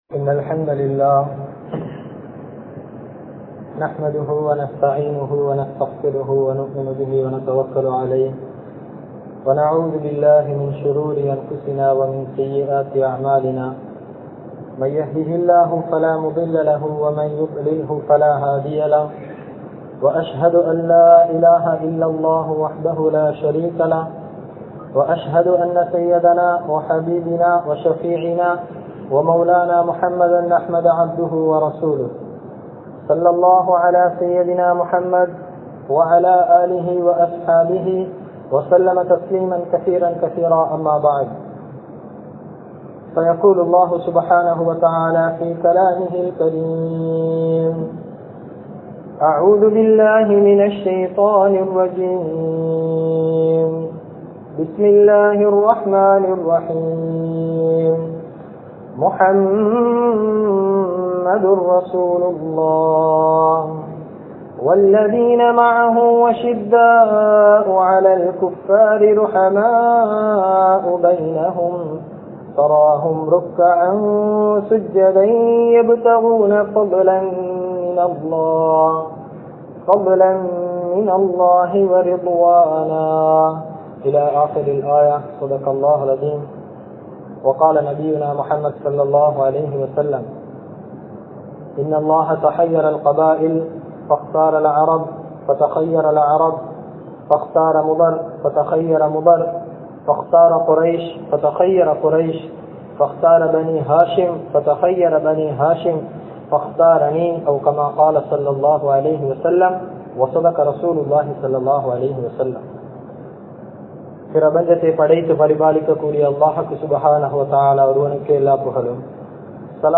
Ungalukku Mun Maathiri Yaar? (உங்களுக்கு முன்மாதிரி யார்?) | Audio Bayans | All Ceylon Muslim Youth Community | Addalaichenai
Majmaulkareeb Jumuah Masjith